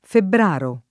febbr#Lo] (region. antiq. febbraro [
febbr#ro]) s. m. — sim. il top. m. Febbraro (Lomb.) e i cogn.